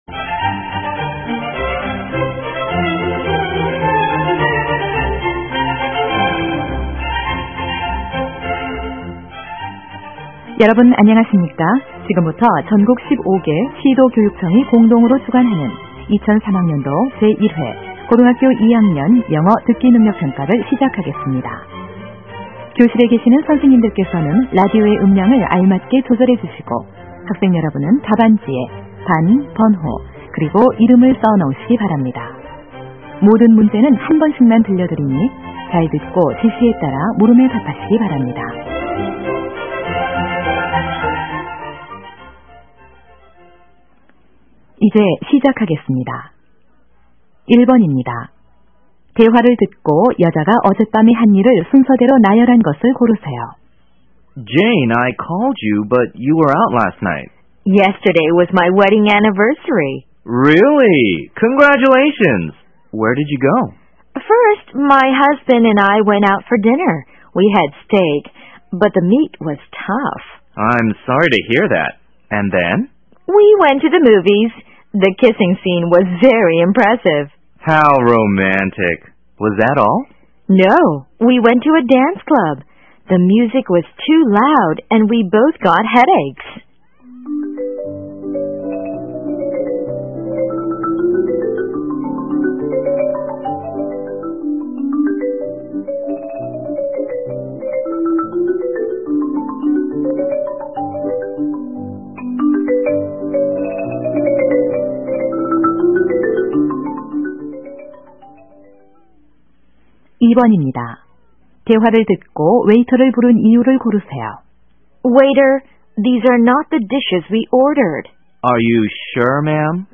2003학년도 1회 2학년 듣기평가